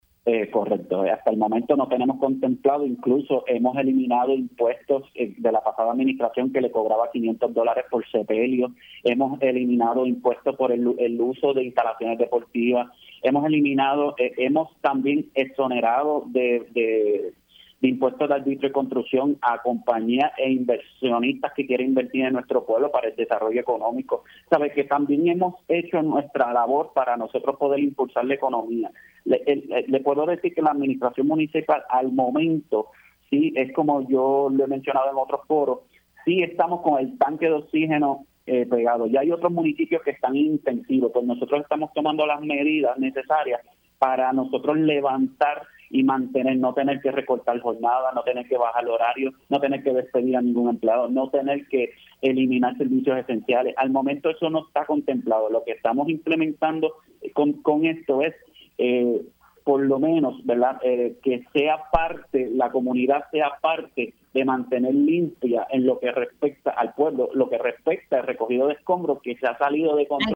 El alcalde de Corozal,  Luis “Luiggi” García informó en Pega’os en la Mañana que debido a los apretos económicos que enfrenta el municipio, comenzarán a implementar una tarifa por servicios de recogido y depósito de escombros y material vegetativo, a partir del próximo 1 de julio.